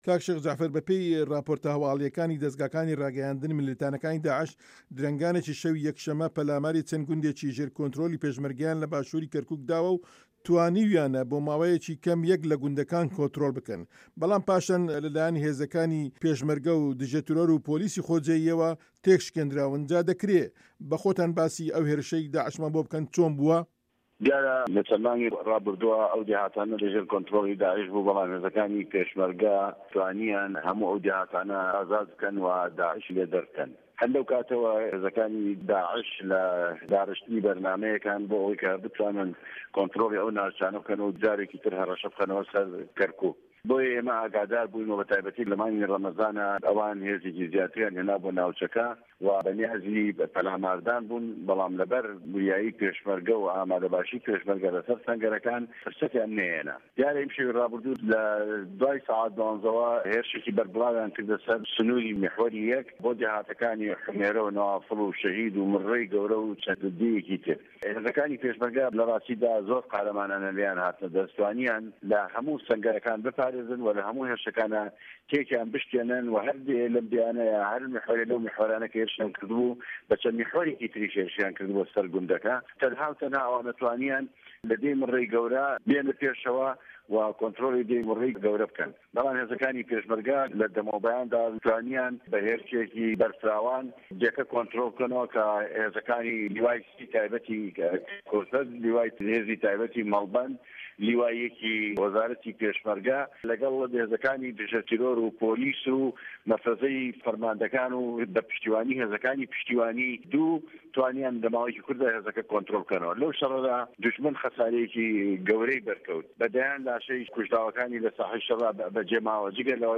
وتووێژ لەگەڵ شێخ جەعفەر